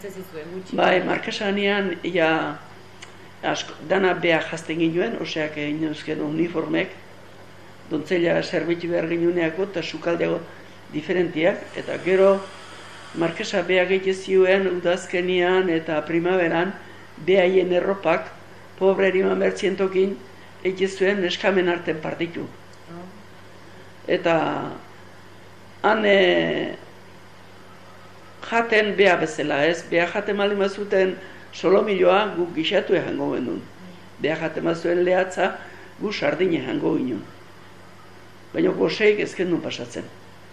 Interviews with women and men who lived the first years of the Franco regime.